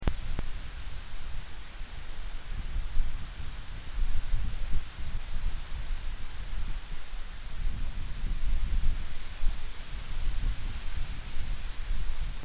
دانلود آهنگ باد 24 از افکت صوتی طبیعت و محیط
جلوه های صوتی
دانلود صدای باد 24 از ساعد نیوز با لینک مستقیم و کیفیت بالا